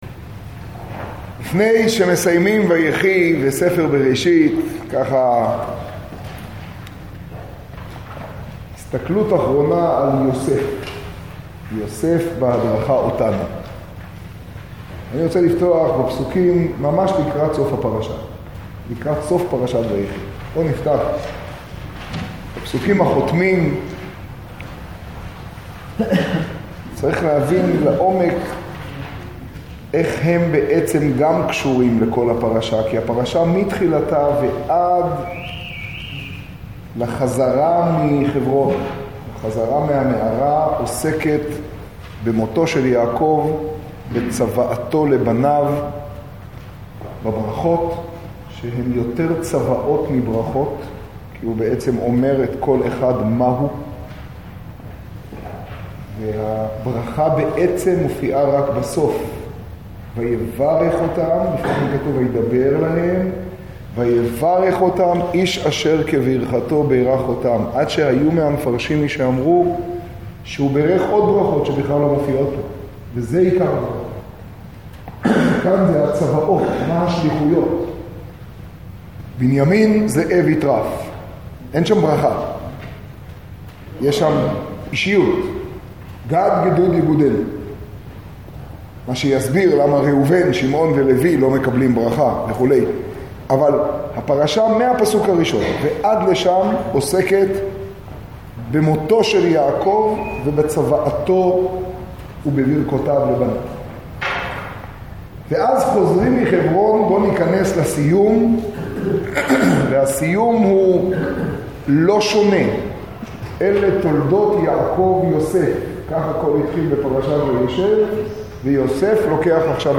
השיעור בירושלים, פרשת ויחי תשעז.
קטגוריה: שיעור, שיעור בירושלים, תוכןתג: בראשית, ויחי, חומש, תשעז